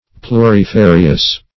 Search Result for " plurifarious" : The Collaborative International Dictionary of English v.0.48: Plurifarious \Plu`ri*fa"ri*ous\, a. [L. plurifarius, fr. L. plus, pluris, many.
plurifarious.mp3